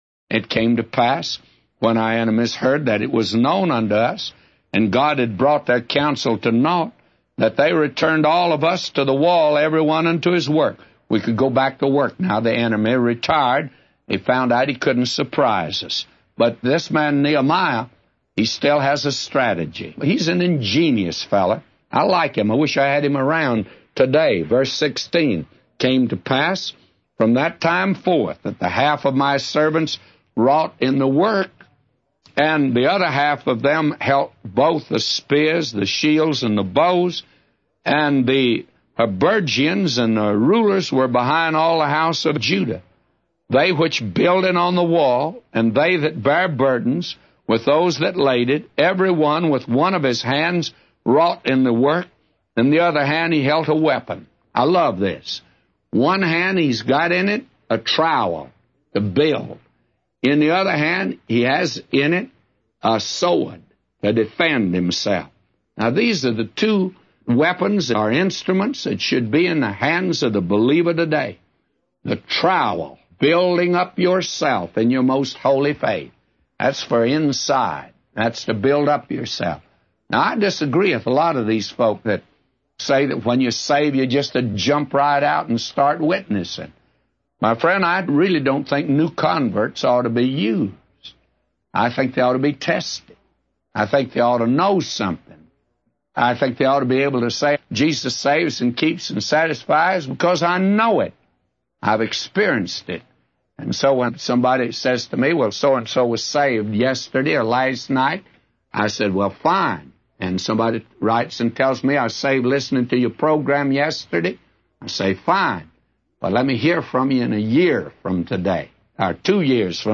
A Commentary By J Vernon MCgee For Nehemiah 4:15-999